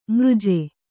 ♪ mřje